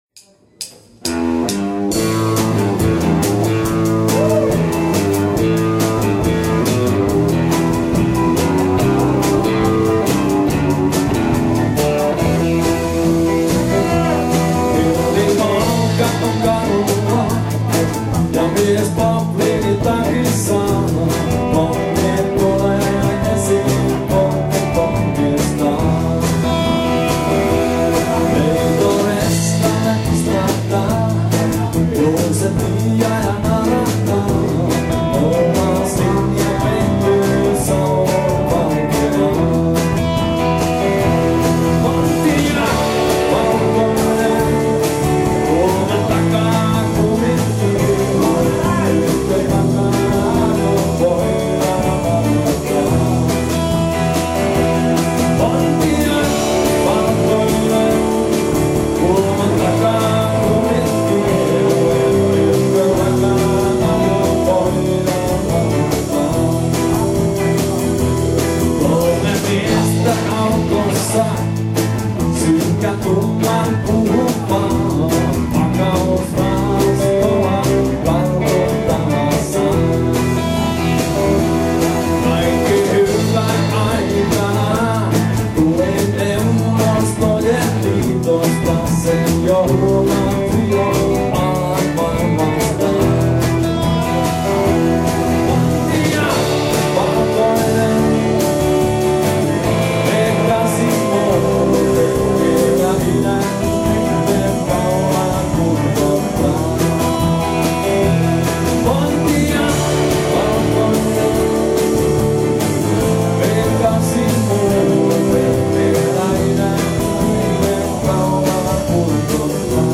Live at Pub Kujakolli in Tampere March 26th 2013
Guitar and vocals
Guitar
Bass
Drums